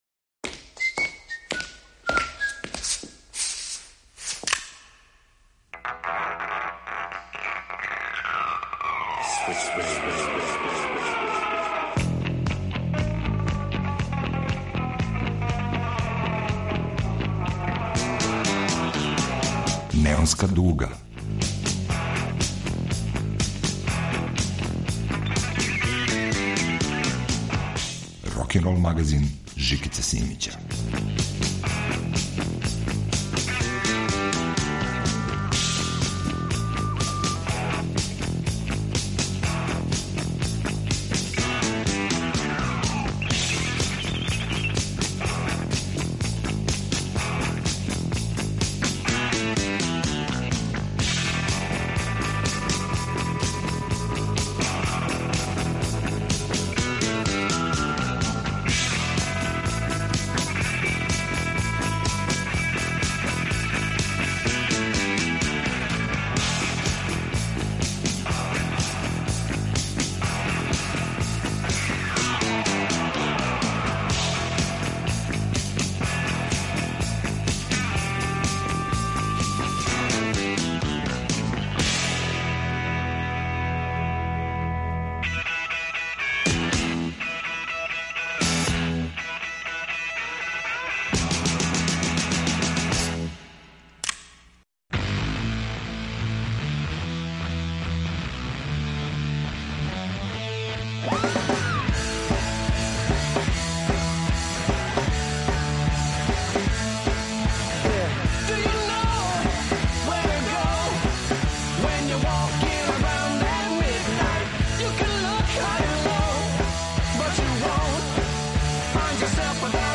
Неонска дуга емитује пробрани избор песама из најновије продукције и повезује их са неким лепим напевима из славне рок прошлости.